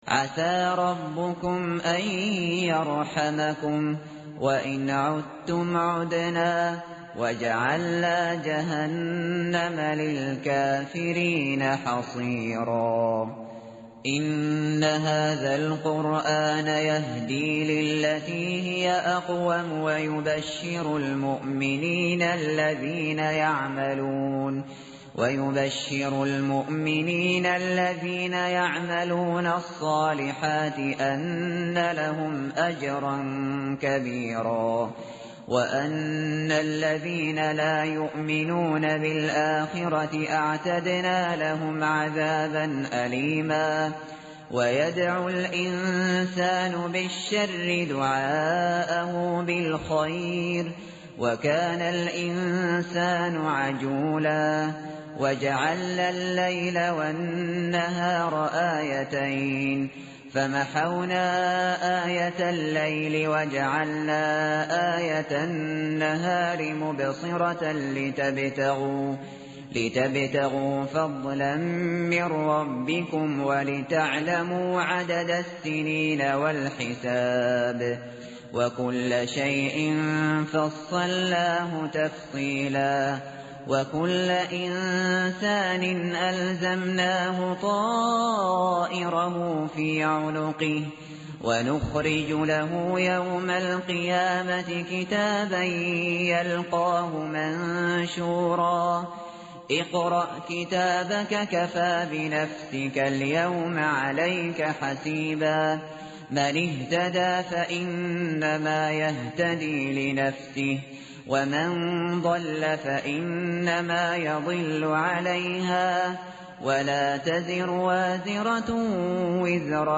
tartil_shateri_page_283.mp3